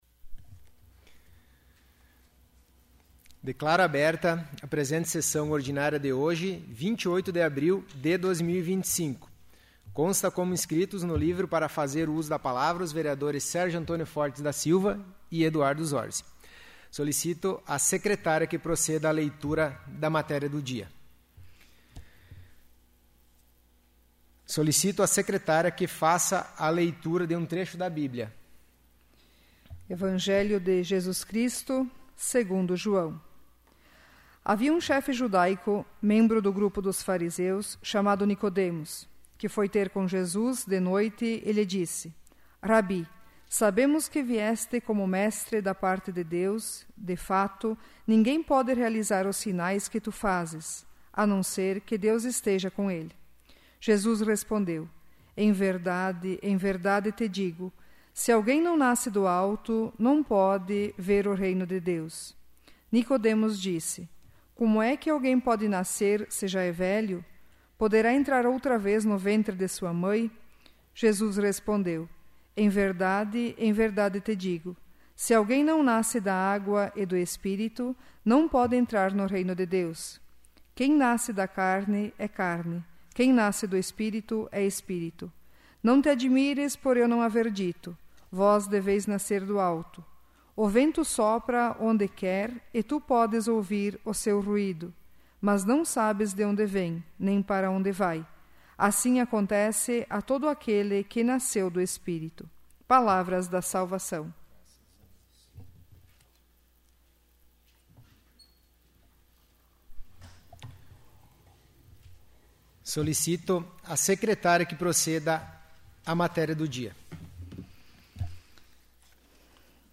'áudio da sessão do dia 28/04/2025'